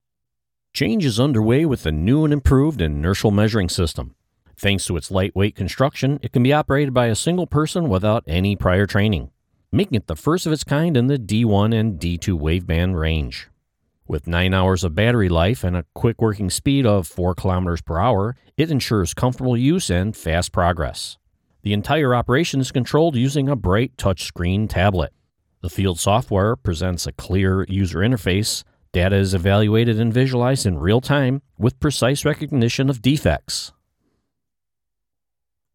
American Male Voice Over Artist
Inertial Measuring, Scientific Explainer
I work out of a broadcast quality home studio with professional recording equipment and a quick turnaround time!